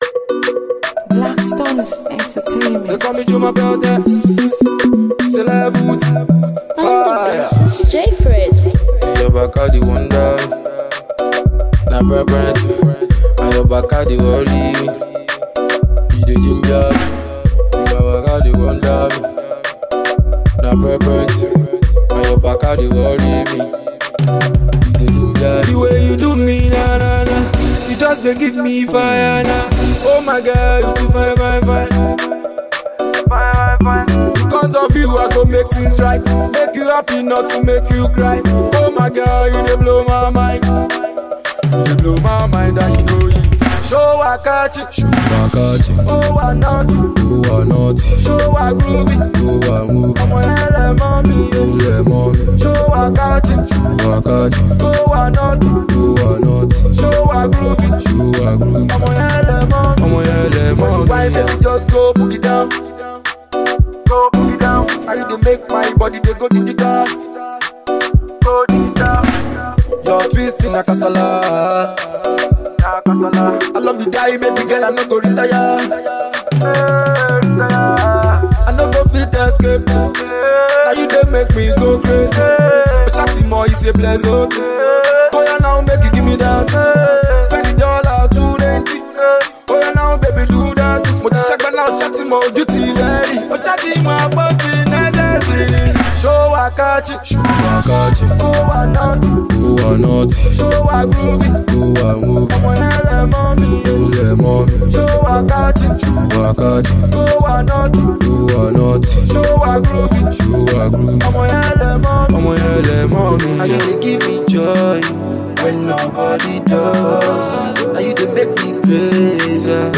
a banging Tune